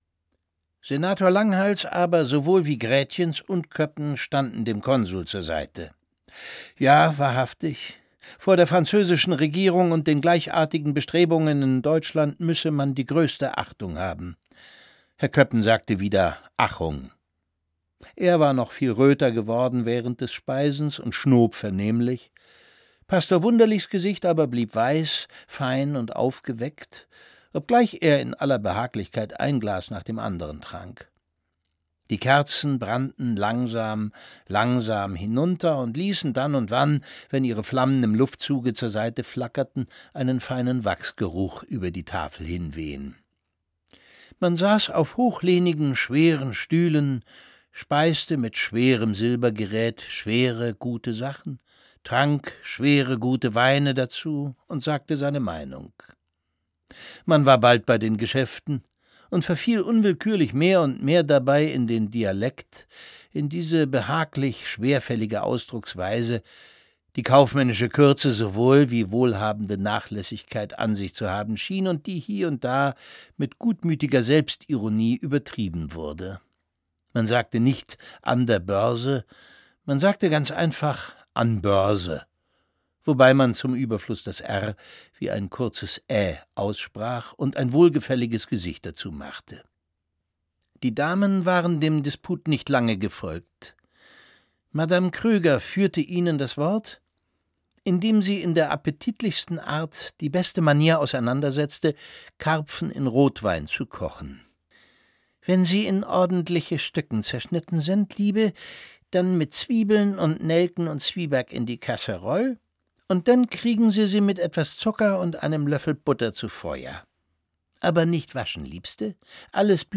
Nur der alte Buddenbrook steht dem Fortschritt skeptisch gegenüber. Es liest Thomas Sarbacher.